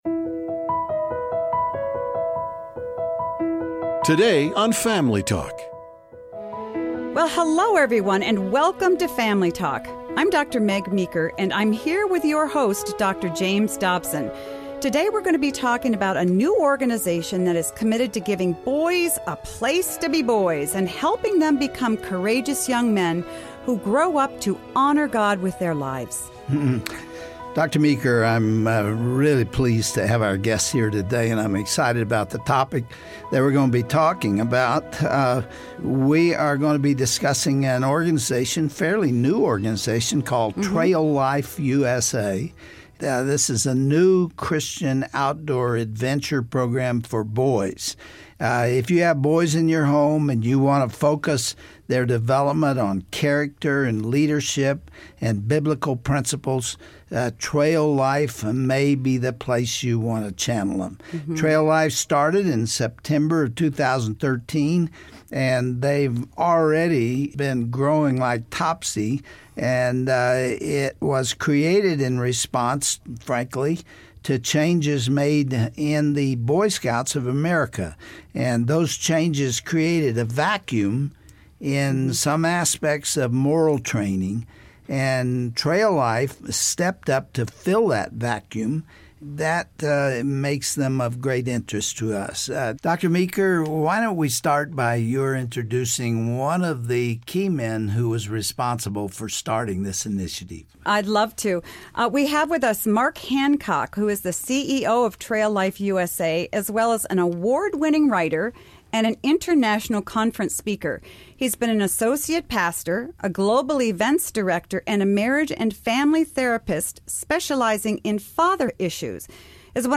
Well you don't want to miss today's Family Talk broadcast.